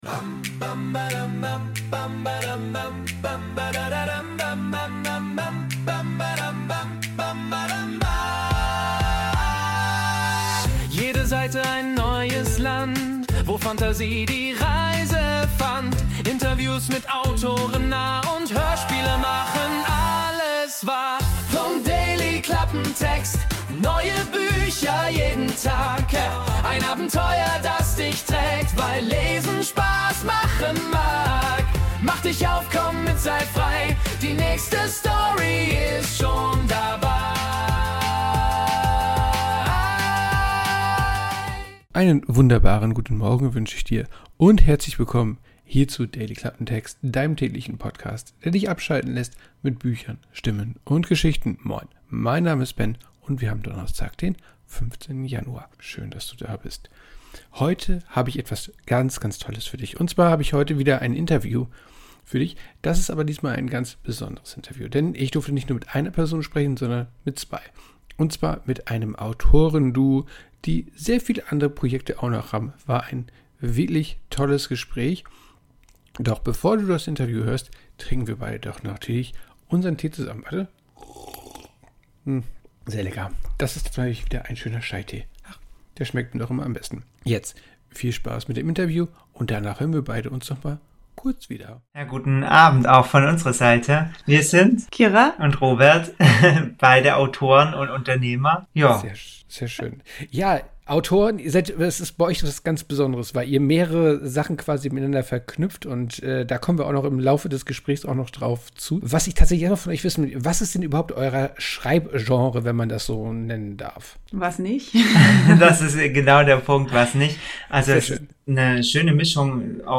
Interview ~ Dailyklappentext Podcast